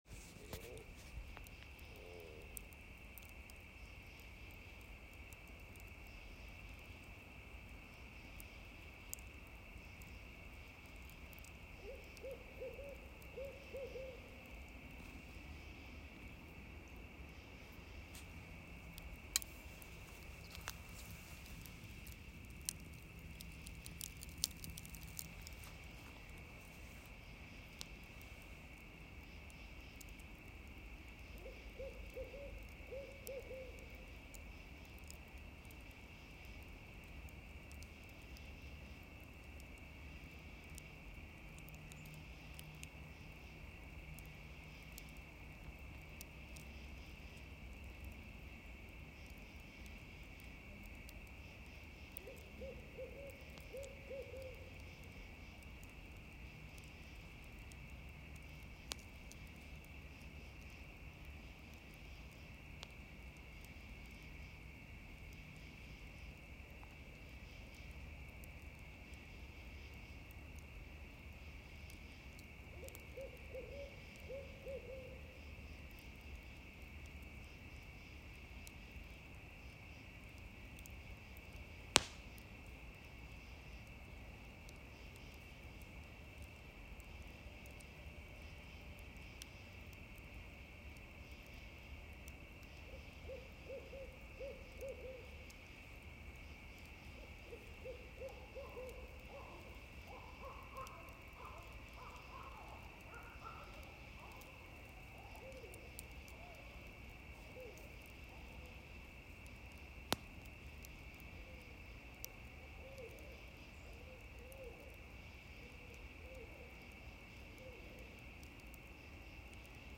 This week, the coaching is BIG and about Trust and Ease, so here are some sounds of the SpiritRiver night, for you to ponder the road to everything you want, through your authentic knowing.
SpiritRiver-524-A-Fire-An-Owl-Crickets-And-Katydids-Oh-My.m4a